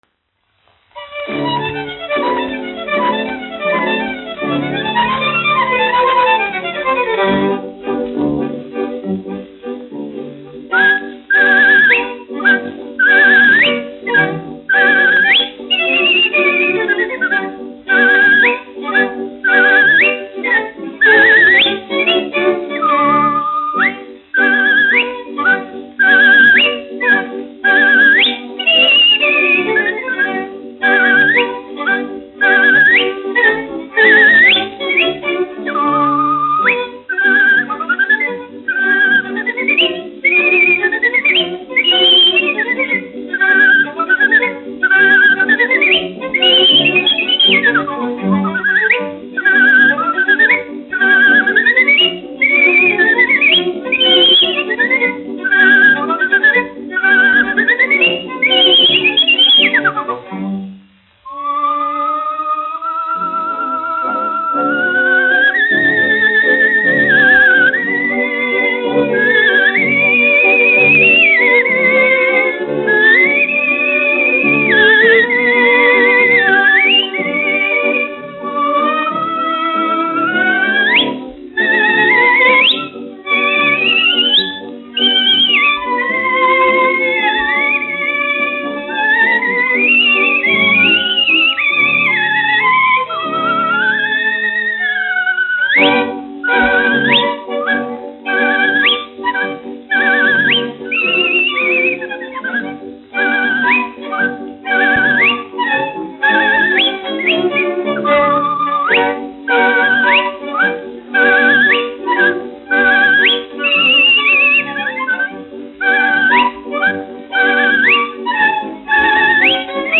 1 skpl. : analogs, 78 apgr/min, mono ; 25 cm
Operas--Fragmenti, aranžēti
[Izpilda] Svilpotājs orķestra pavadījumā